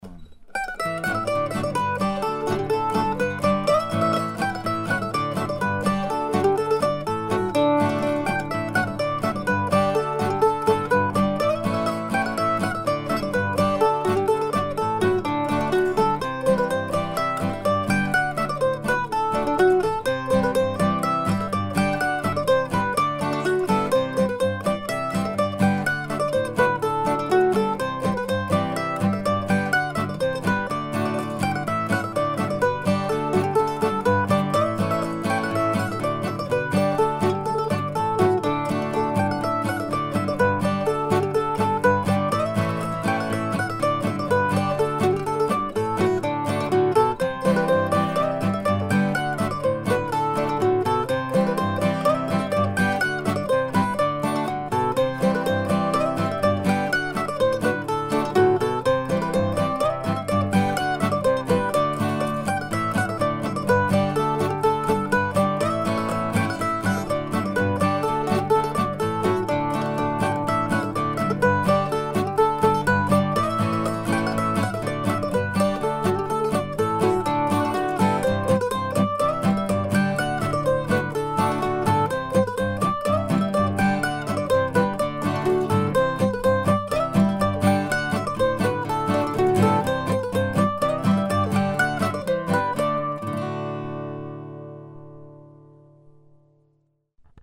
I wrote it to be played at a relaxed pace but yesterday I recorded it at more of a contra dance tempo.
Both of these tunes were recorded on Sunday morning, Feb. 11 using my Pomeroy mandolin.